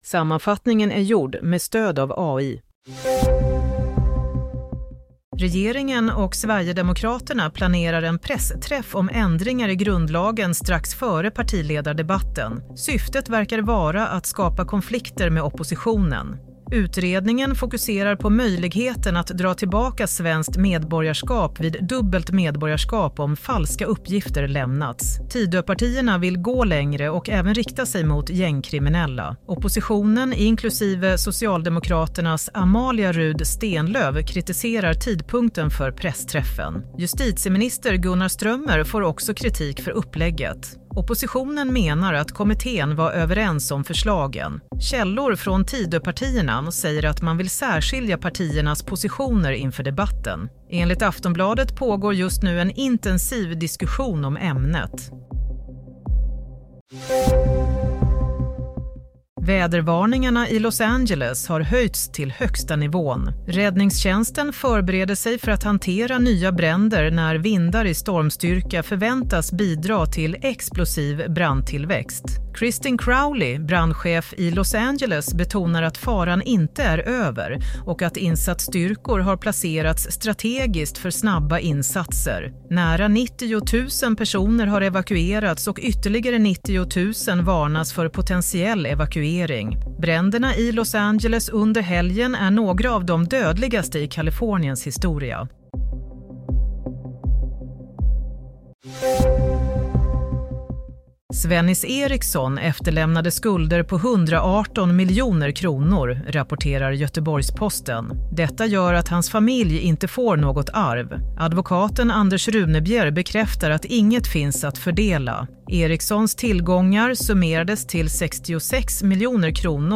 Nyhetssammanfattning - 14 januari 16:00